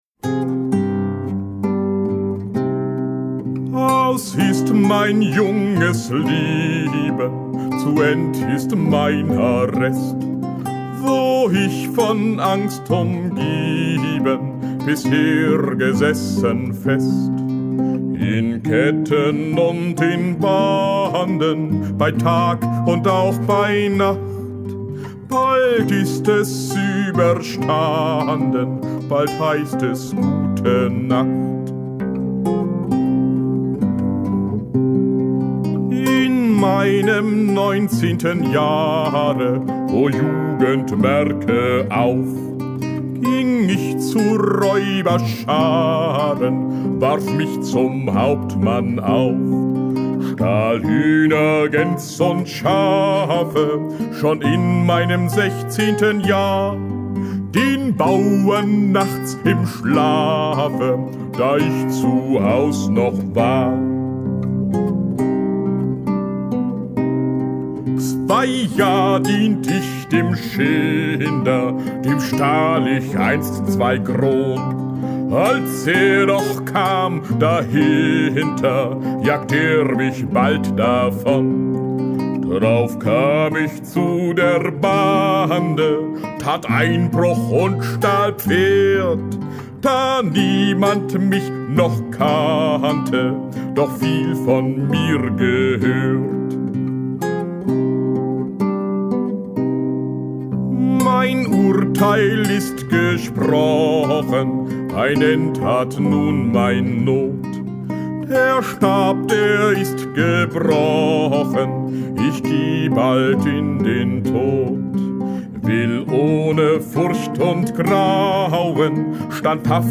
Lieder Aus ist mein junges Leben Das ist der Schinderhannes